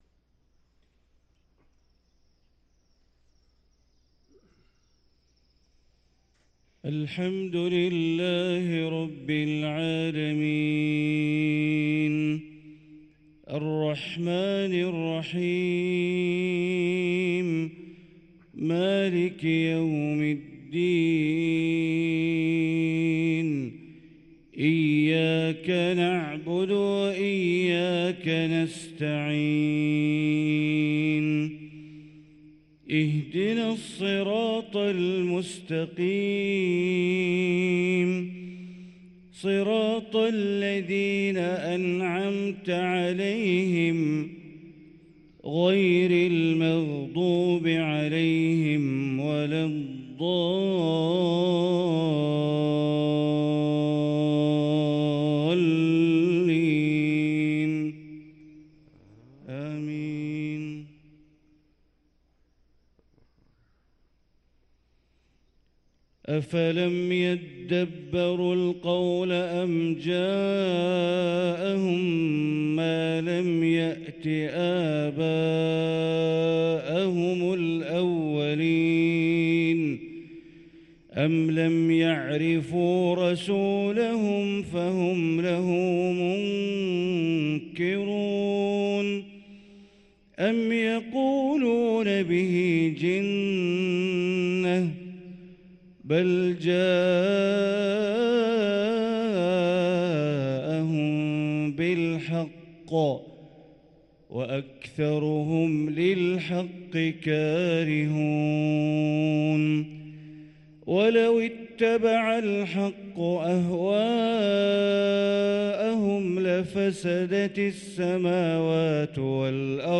صلاة العشاء للقارئ ماهر المعيقلي 19 شعبان 1444 هـ